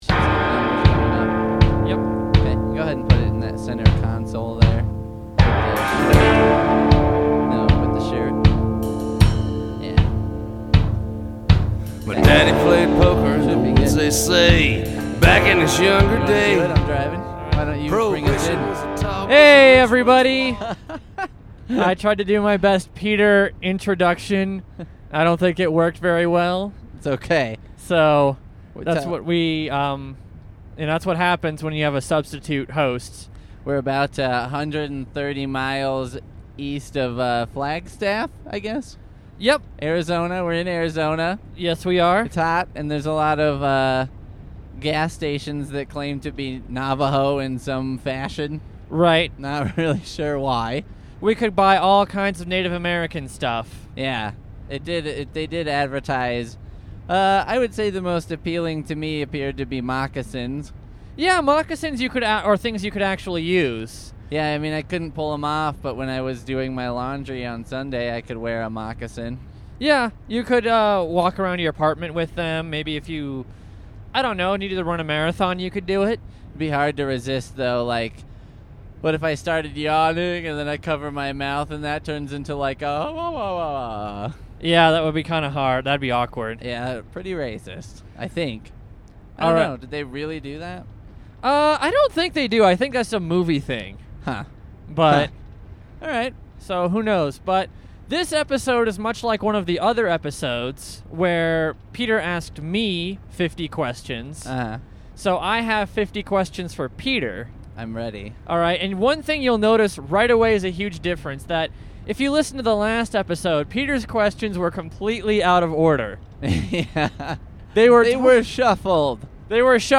Second part of our road show. This time, I’s get asked the 50 questions. We lost about the last 20 minutes of audio here because of some technical difficulties.